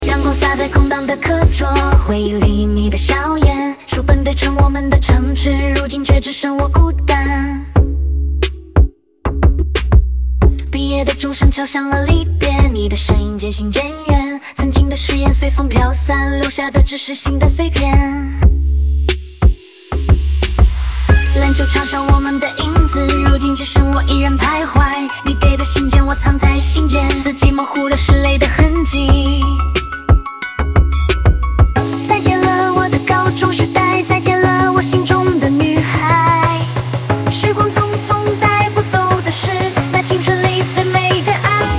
人工智能生成式歌曲